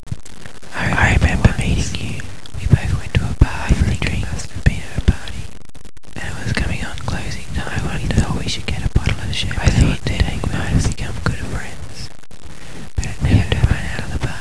Each one sensors the movement of any viewer and plays a whispered monologue through the speakers as the viewer passes by.
There are 4 boxes with latex speakers and movement sensors along the corridor.